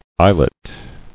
[is·let]